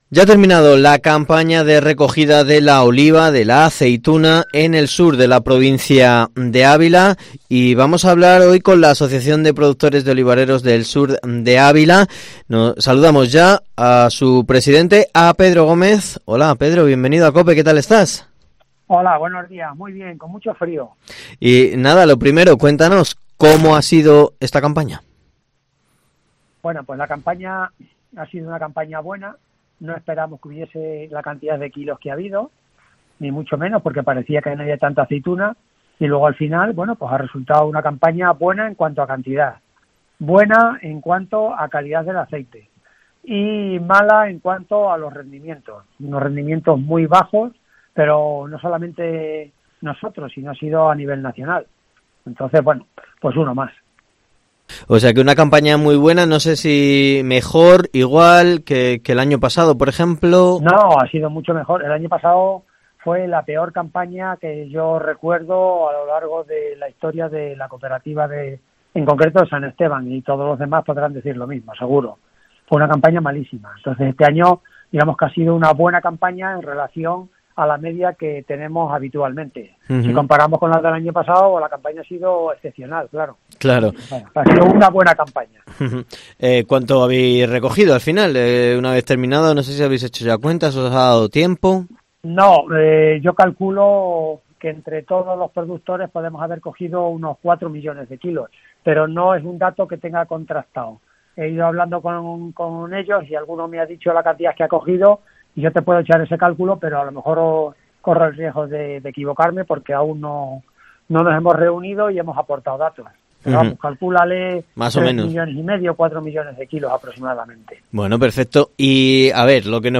Herrera en COPE en Ávila ENTREVISTA